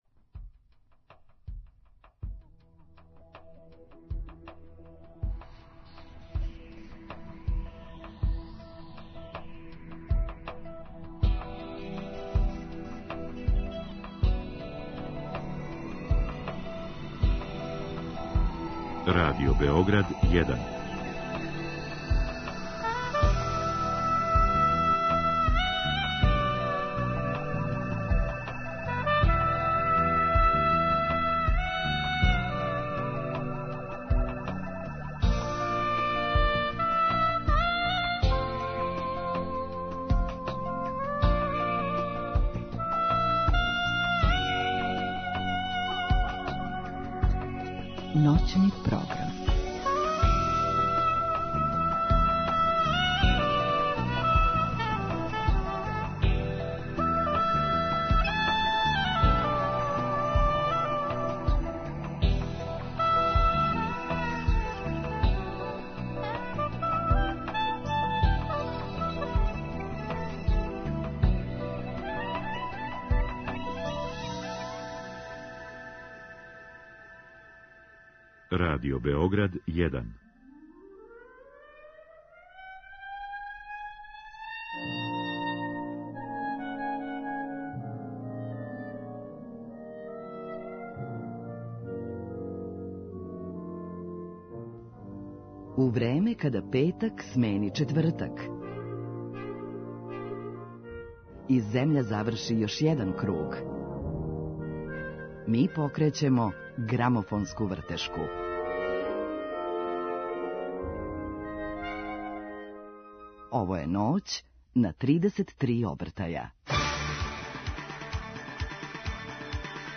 Њен кант-ауторски опус обухвата преко 400 песама и инструментала, а у Ноћи на 33 обртаја имаћемо прилике да чујемо неке од њих. Сазнаћемо зашто се одлучила да солистички каријеру настави на енглеском језику, како настају њене песме али и како се бори са мултипла склерозом.